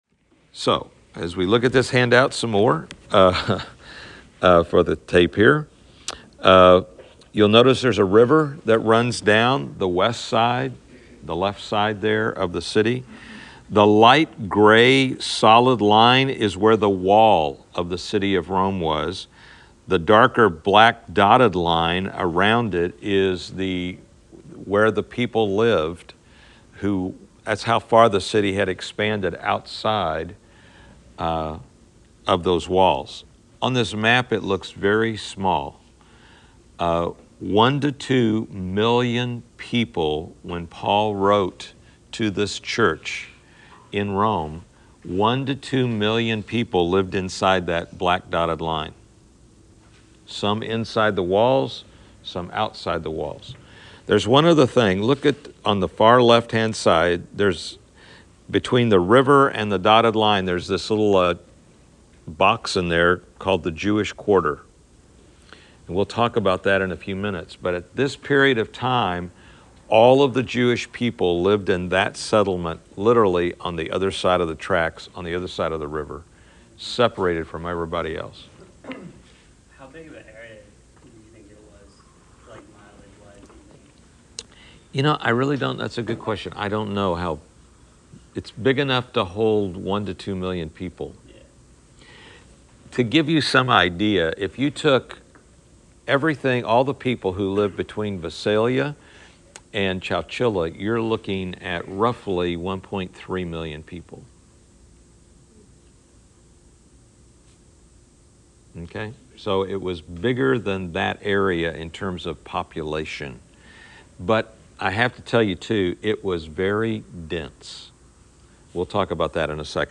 Class Session Audio October 31